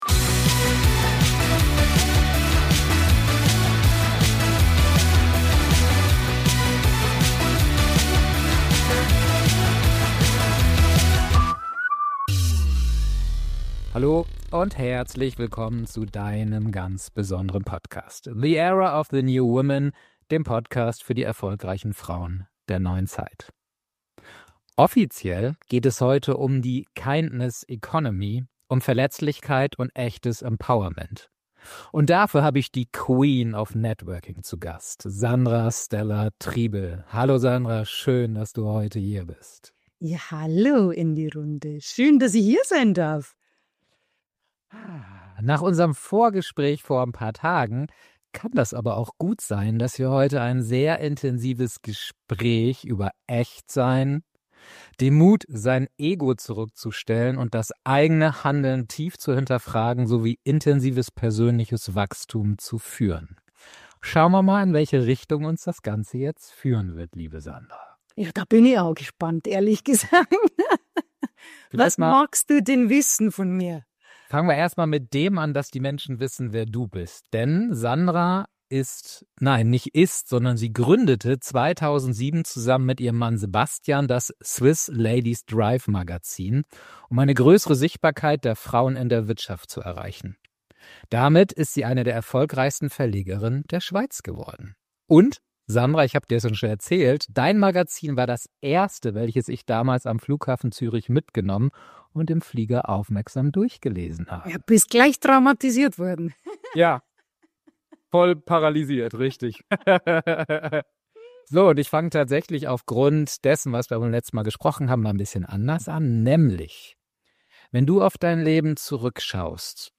Dieses Intensive-Interview ist kein Ratgeber.